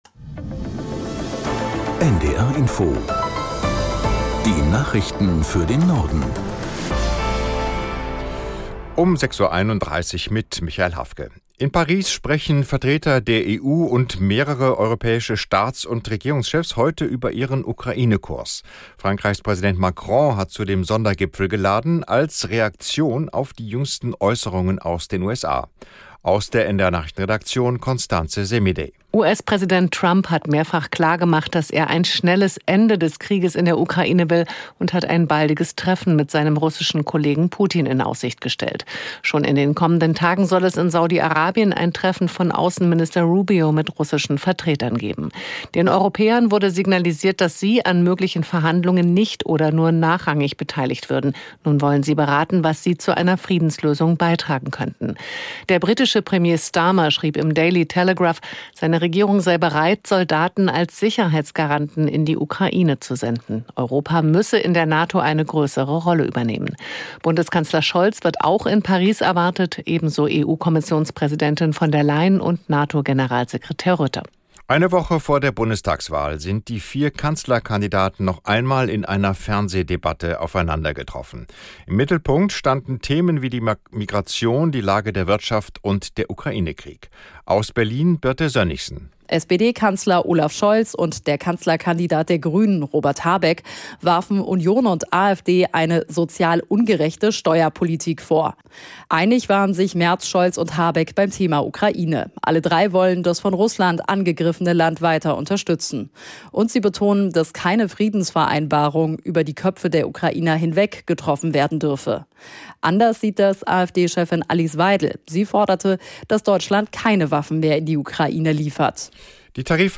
Nachrichten - 17.02.2025